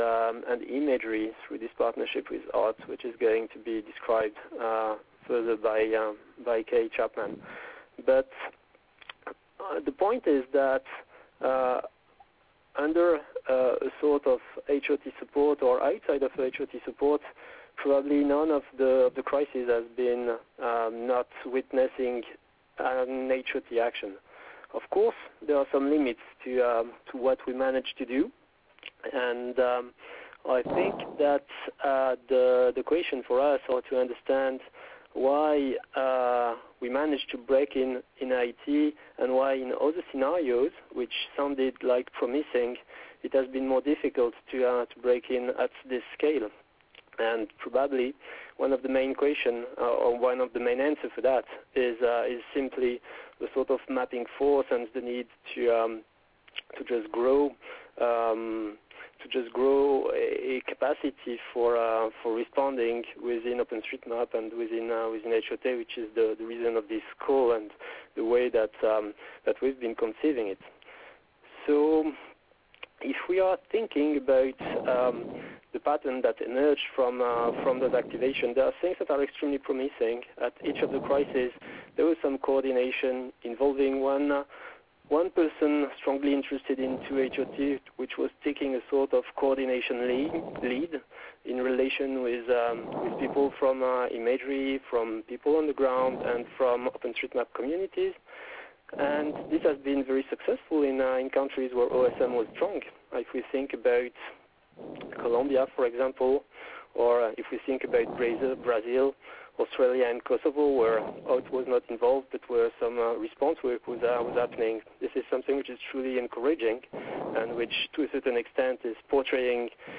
The Humanitarian Openstreetmap Team had a conf call
Unfortunately, it is missing 3 or 4 minutes at the beginning (I had to realize nobody else was to record the call and launch a few pieces of software) and 20 seconds at the end. I did no post-processing.